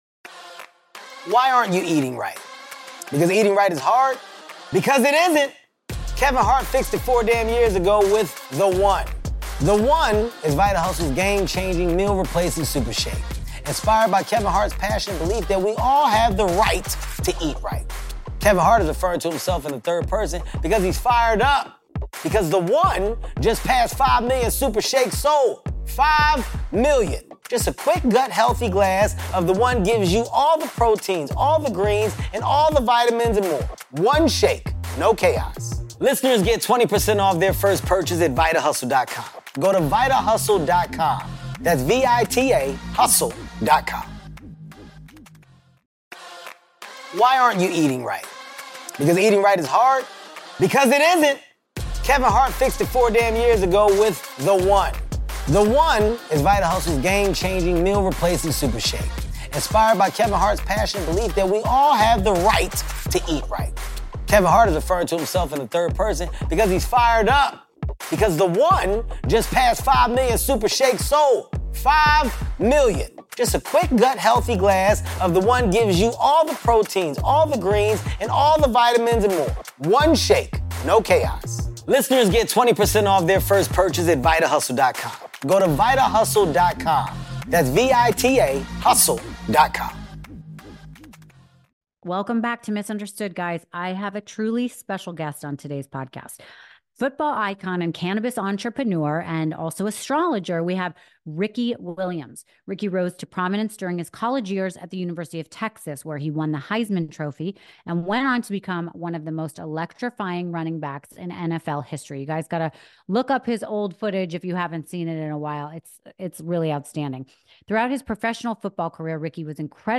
Today's guest is NFL legend Ricky Williams!